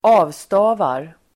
Uttal: [²'a:vsta:var]
avstavar.mp3